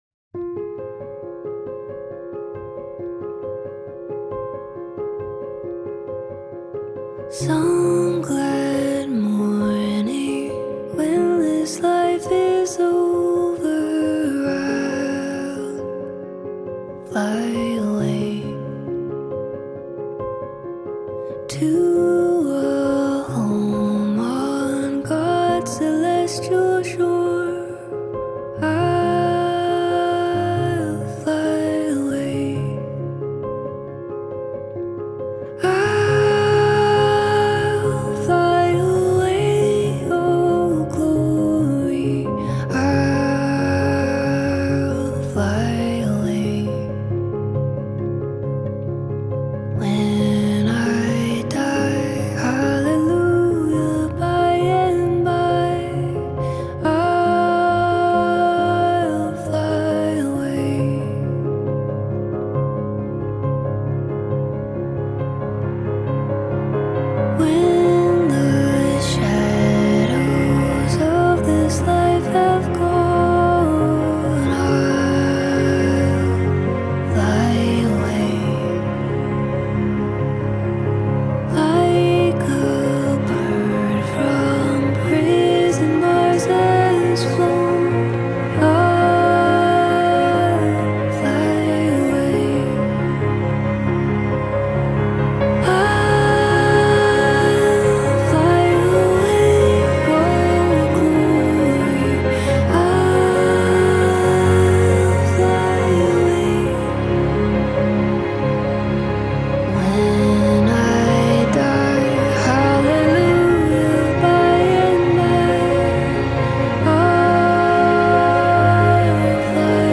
10 просмотров 23 прослушивания 0 скачиваний BPM: 68